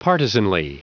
Prononciation du mot partisanly en anglais (fichier audio)